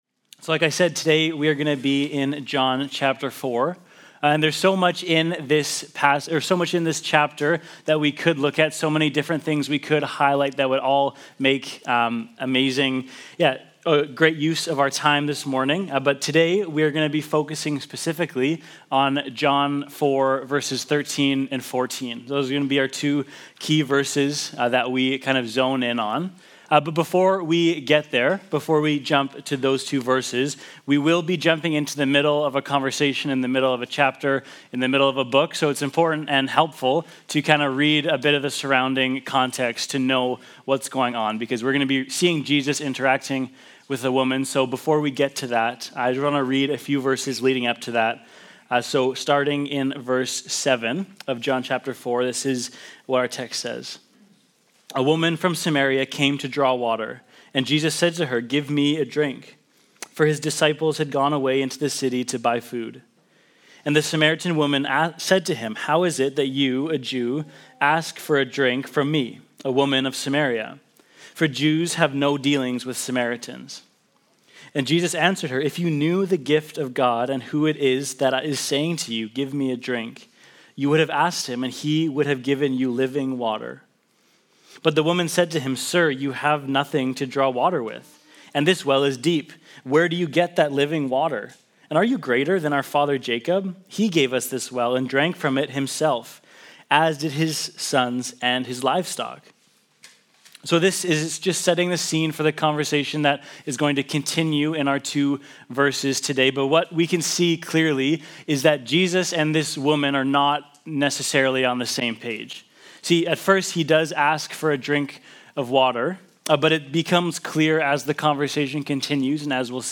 This podcast is where you'll find audio from our Sunday morning sermons and other times of teaching throughout the year.